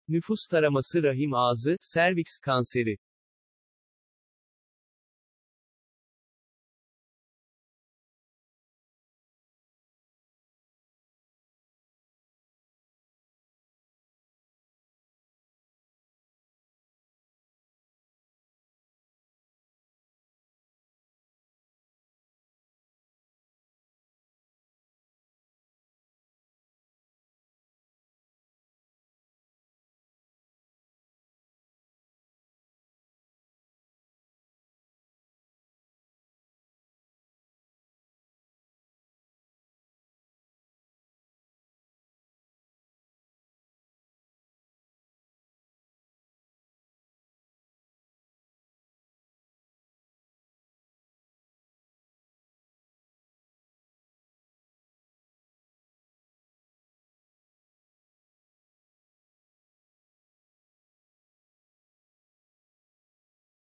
Animatie